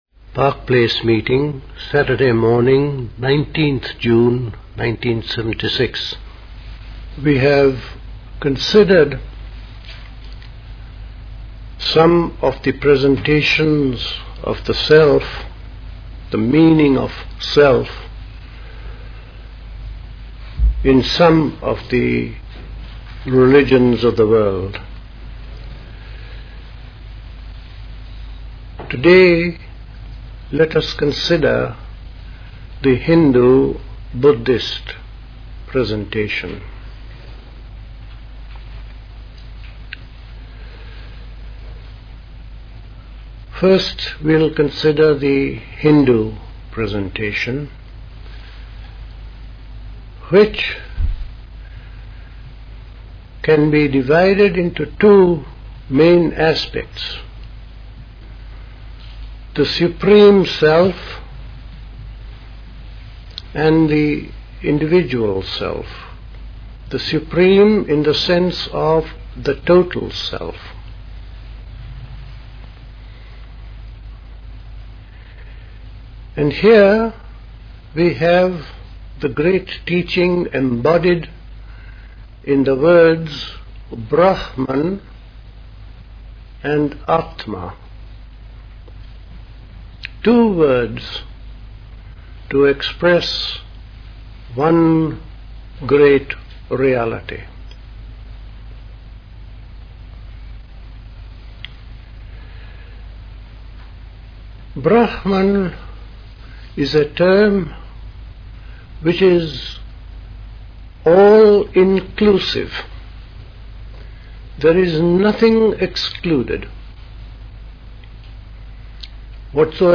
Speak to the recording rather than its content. Recorded at the 1976 Park Place Summer School.